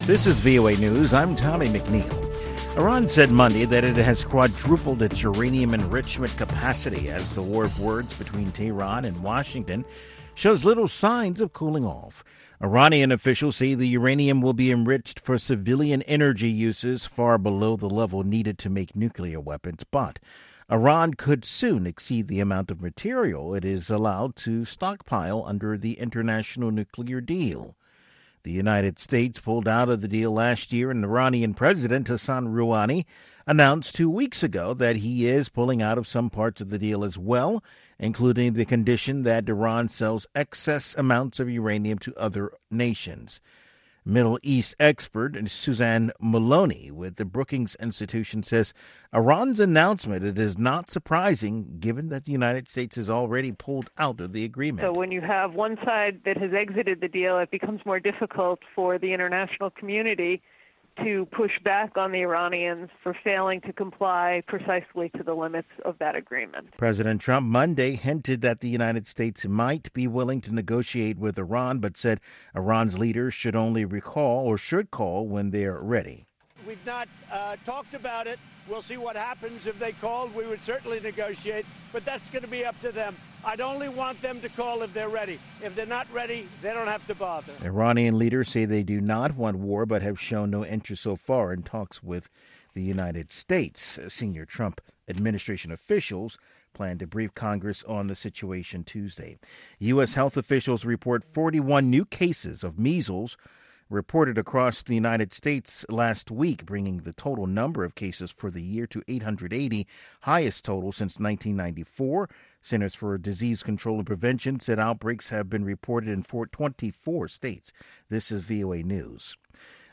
Each morning, Daybreak Africa looks at the latest developments on the continent, starting with headline news and providing in-depth interviews, reports from VOA correspondents, sports news as well as listener comments.